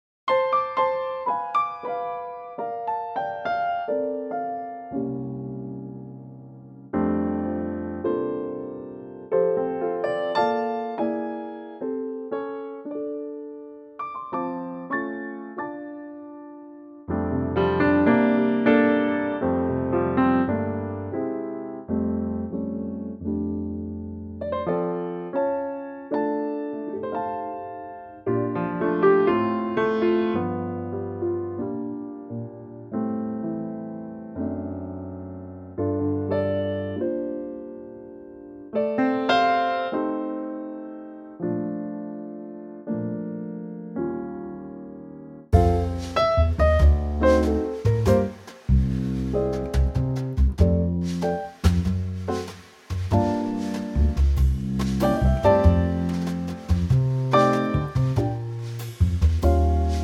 Unique Backing Tracks
2 bar piano intro and into the verse at 5 seconds
key - F - vocal range - F to Bb